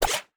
Tab Select 15.wav